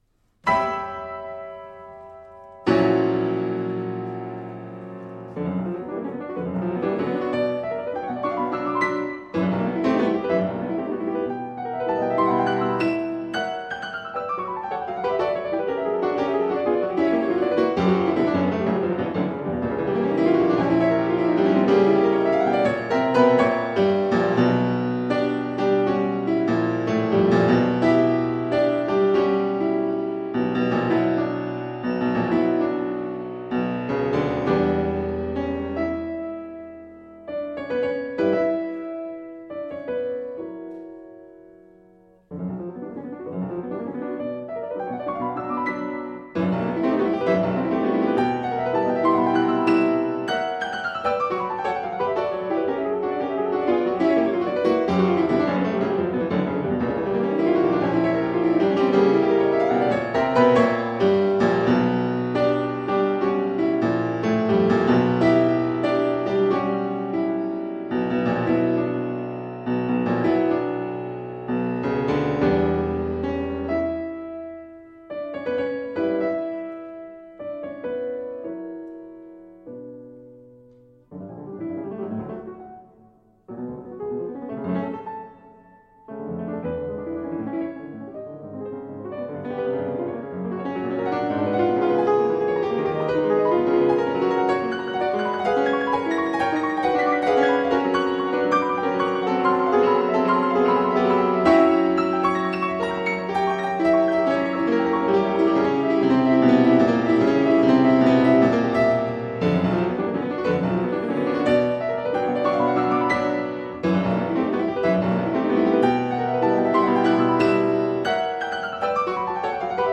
No parts available for this pieces as it is for solo piano.
Piano  (View more Advanced Piano Music)
Classical (View more Classical Piano Music)